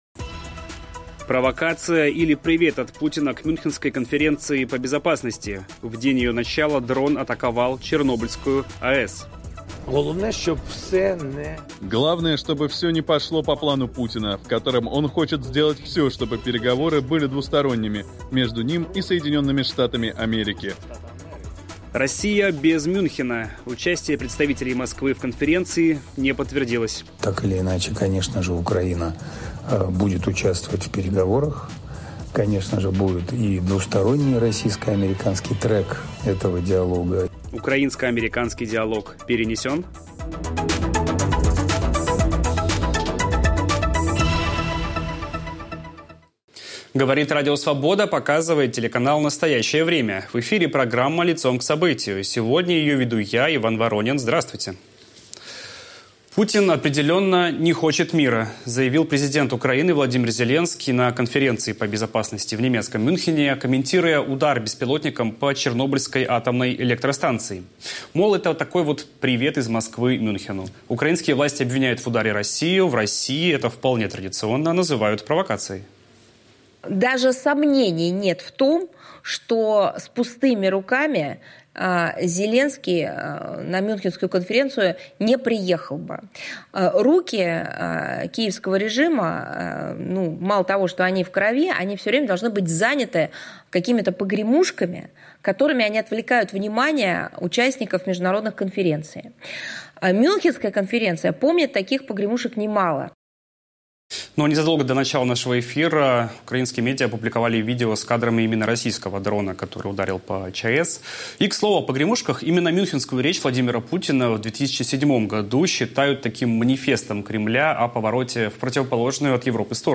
О том, чего от встреч украинского и американского руководства ждут в России и о том, какие выводы из происходящего делает российское антивоенное движение, говорим с одним из приглашённых гостей конференции, российским оппозиционным политиком Андреем Пивоваровым.